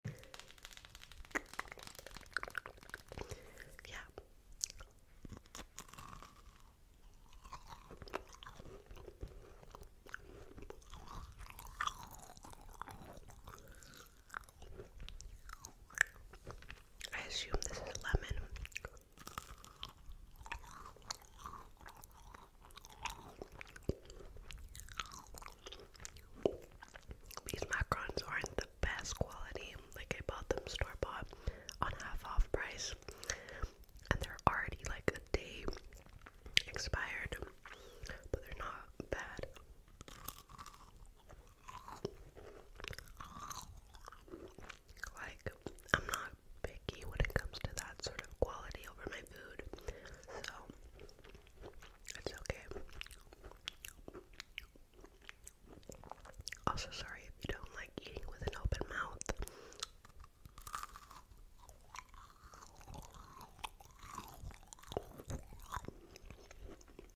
Eating macaroon mouth sounds ASMR